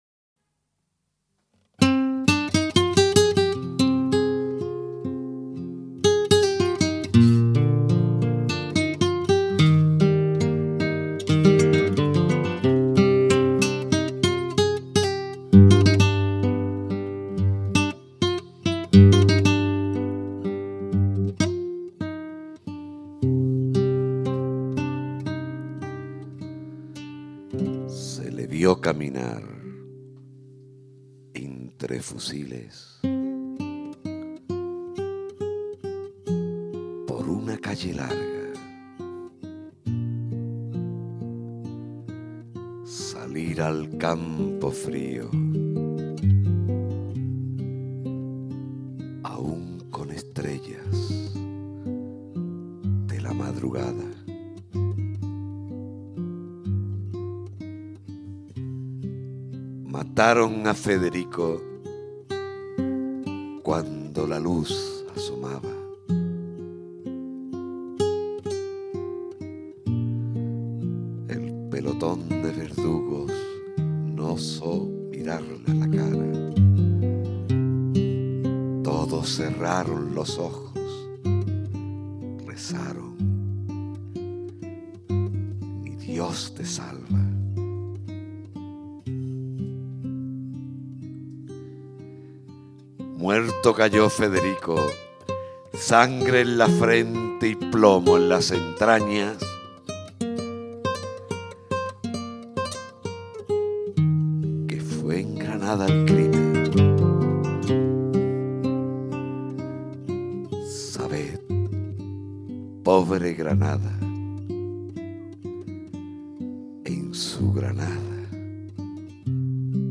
Actor rapsoda